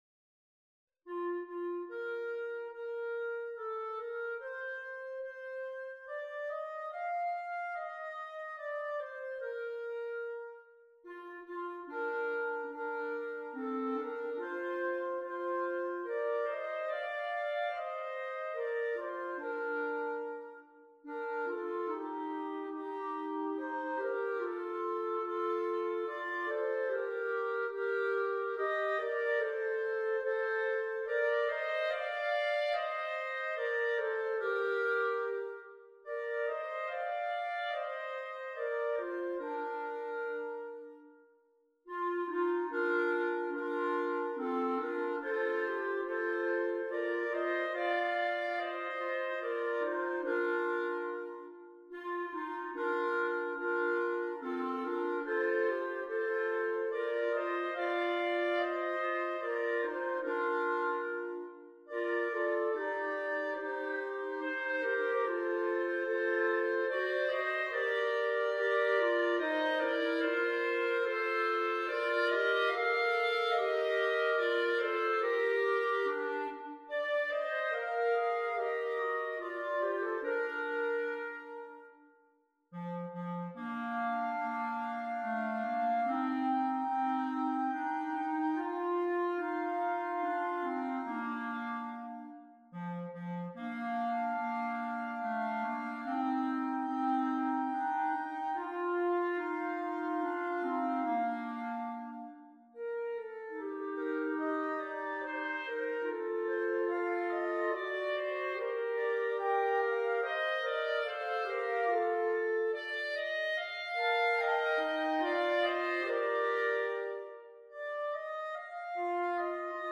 a tender Polish carol arranged for clarinet trio